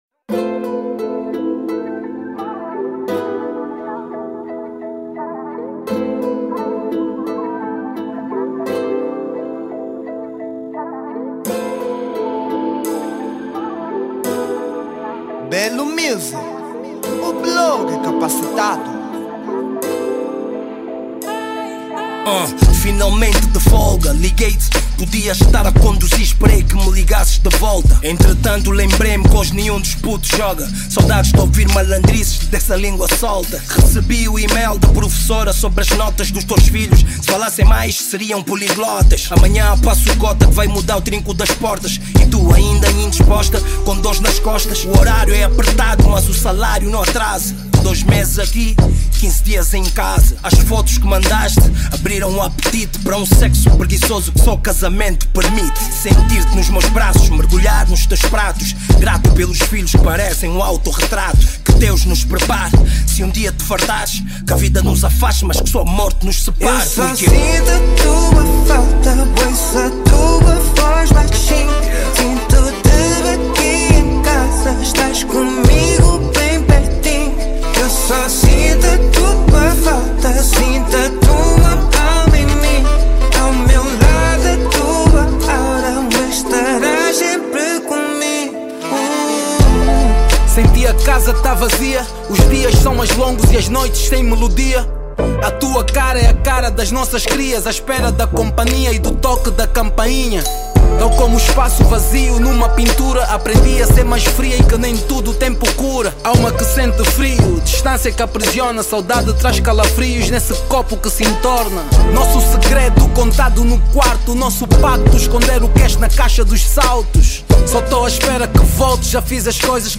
Género : Rap